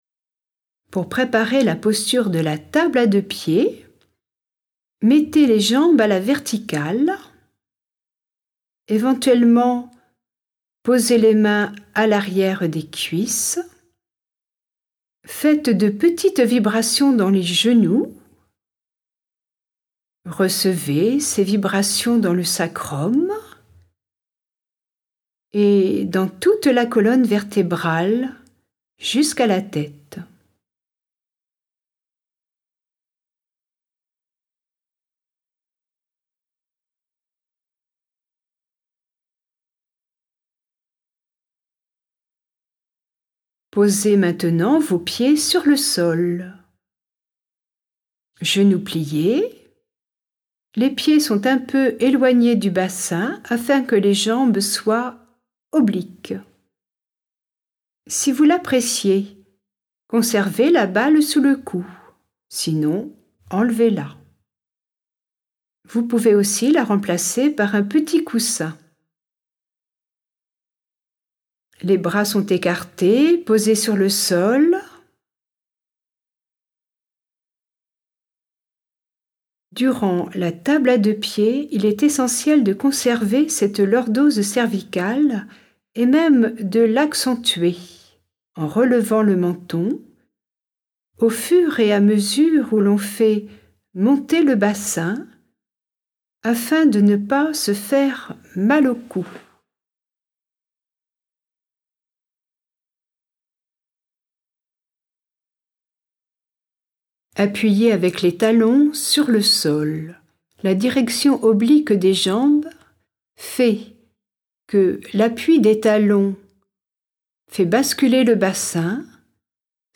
Exercice audio pour pratiquer chez soi